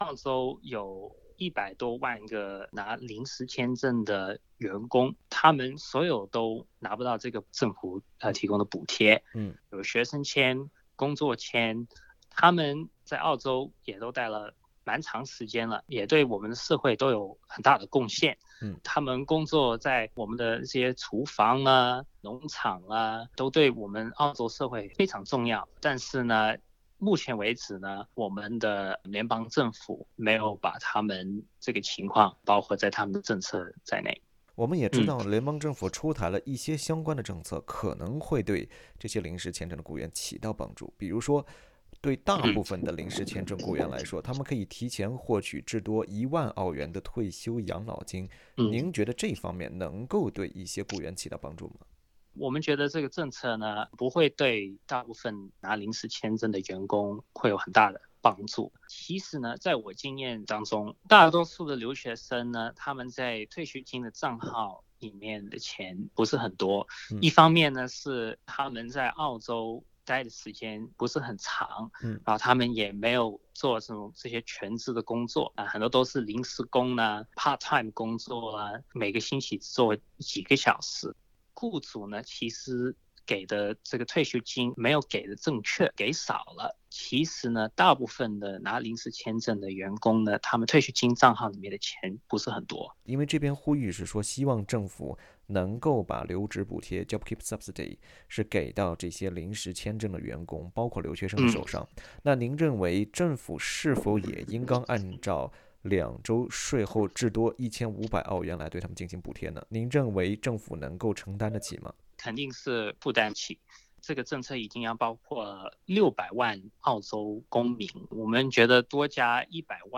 migrant_worker_interview.mp3